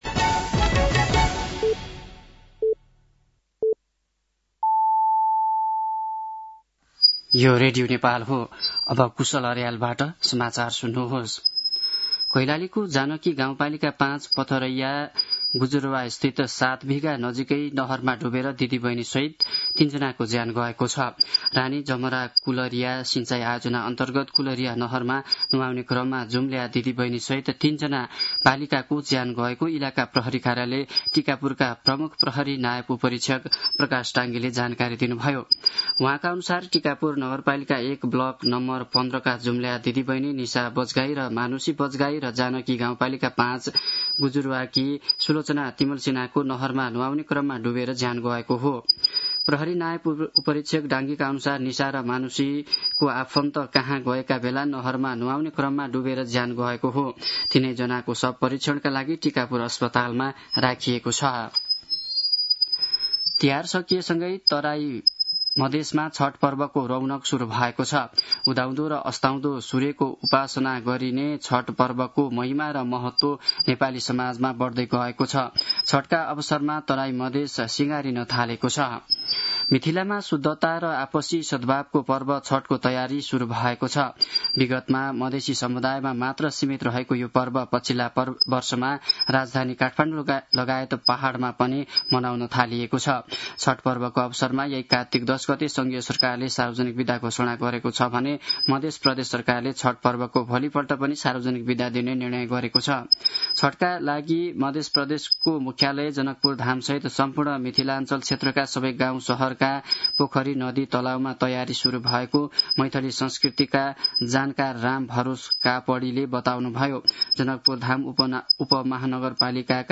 साँझ ५ बजेको नेपाली समाचार : ७ कार्तिक , २०८२
5-pm-nepali-news-7-07.mp3